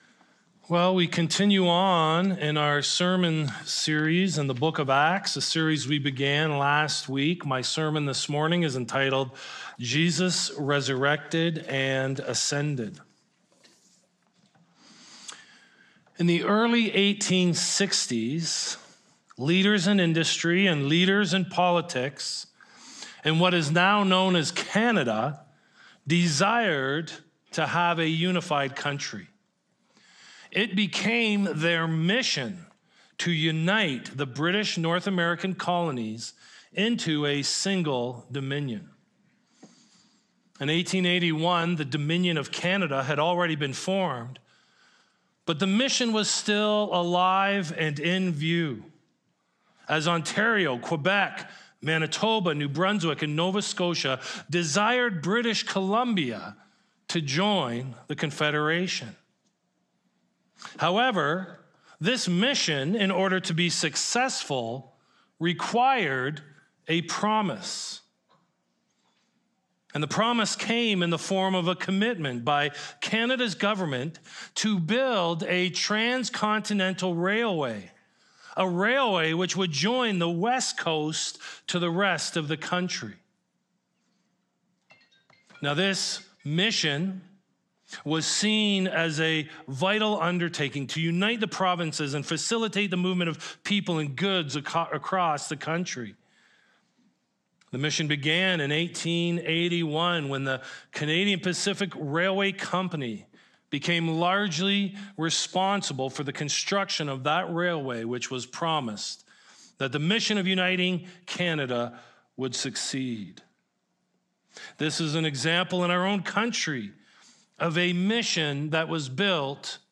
1 Sermon - March 20, 2024 37:50